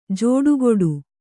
♪ jōḍugoḍu